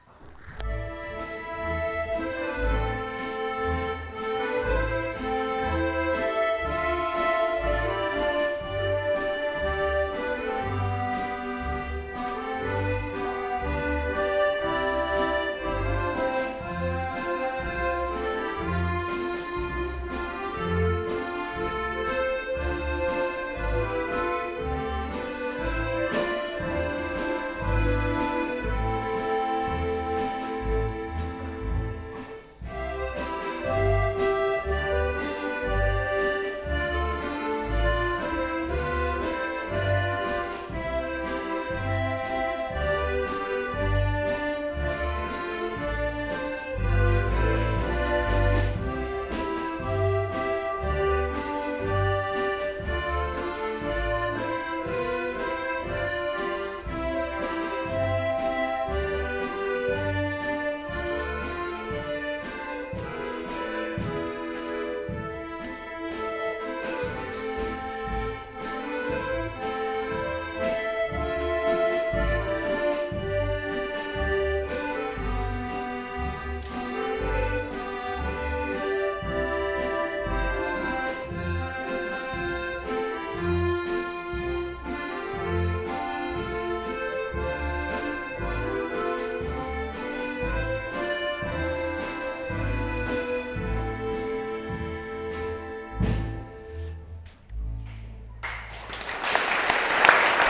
Konzerte in Graz, Wagrain, Feldkirchen, Kumberg, seit 2017
Vom Kinderorchester: